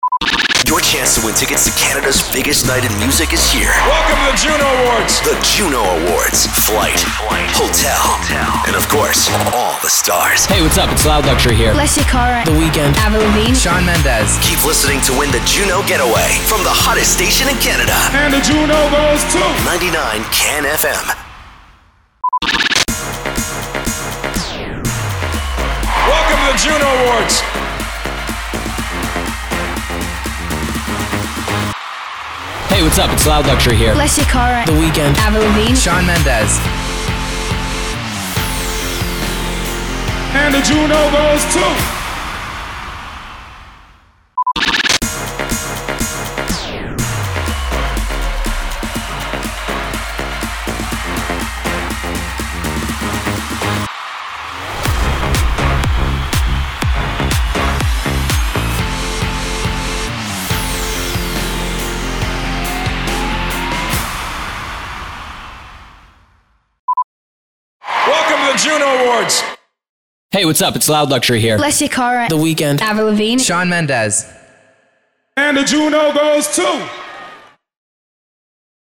069 – SWEEPER – JUNO AWARDS